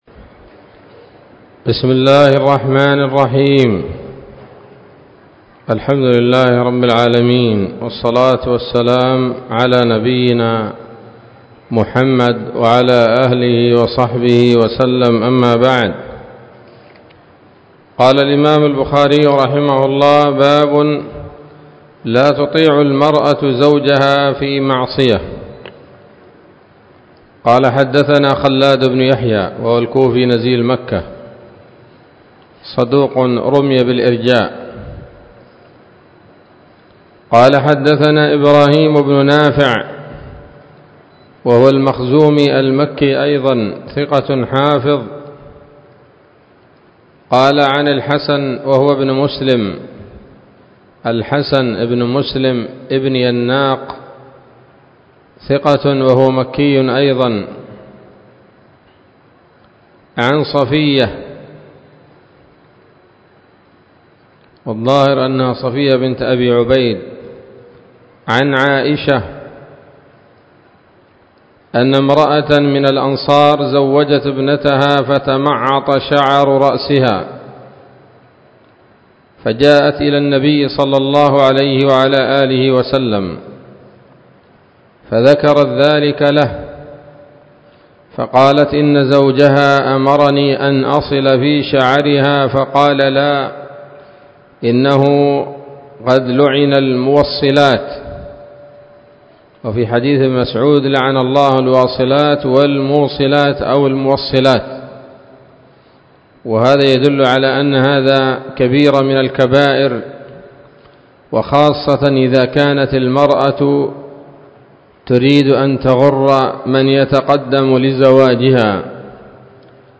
الدرس السابع والسبعون من كتاب النكاح من صحيح الإمام البخاري